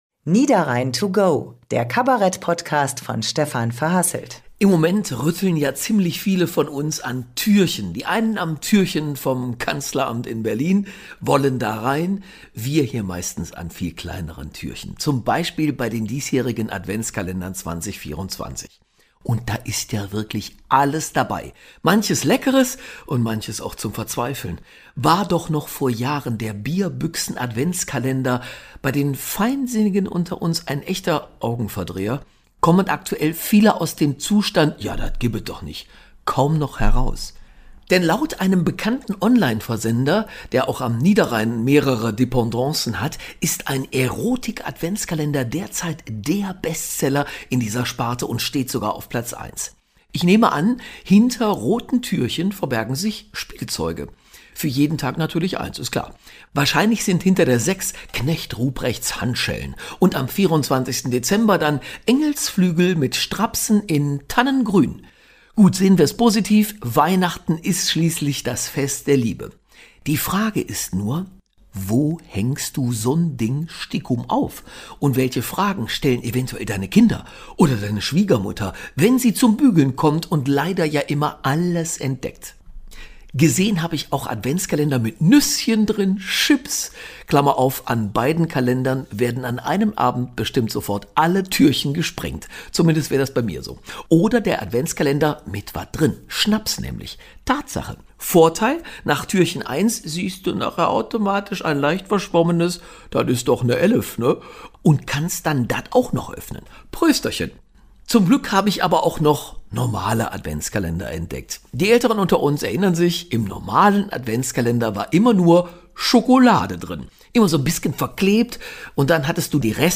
Kabarett-Podcast "Niederrhein to go